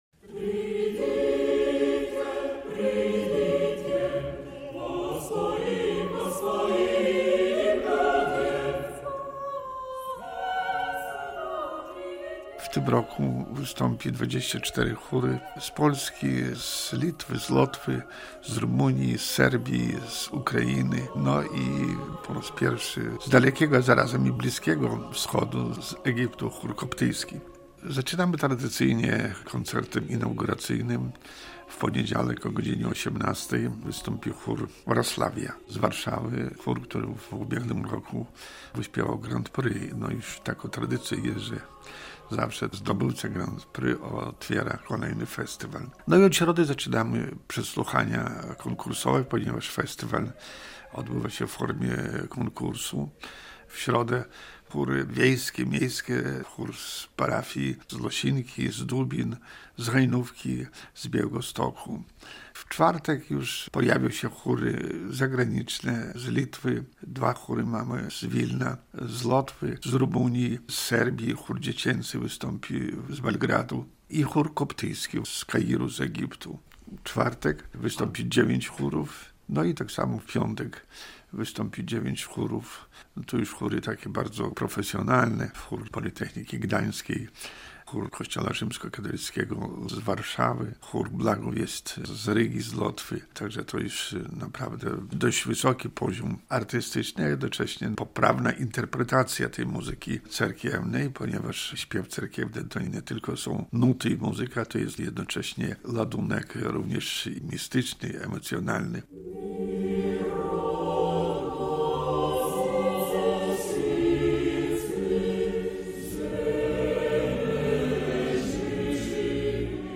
Z księdzem mitratem